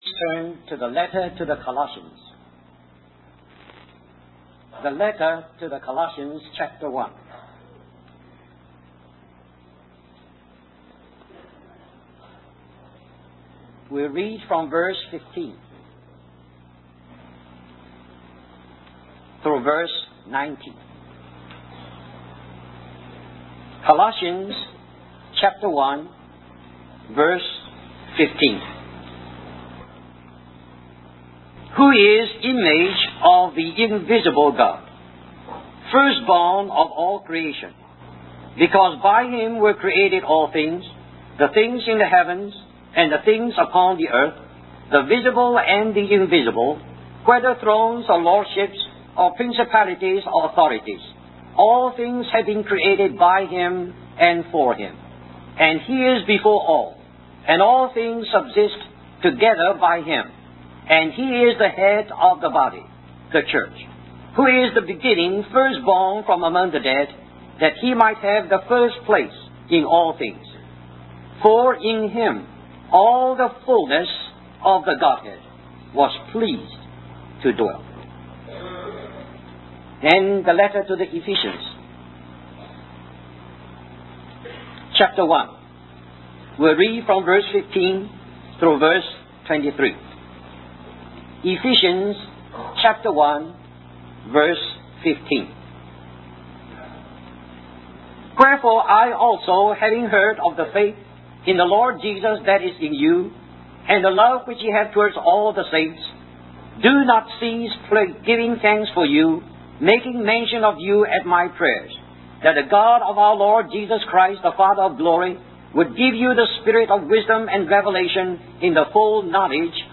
In this sermon, the speaker emphasizes the importance of having a vision in our spiritual lives. He uses the analogy of a farmer using a goat to gently touch the leg of an ox to show that we need discipline and to recognize that we have a master.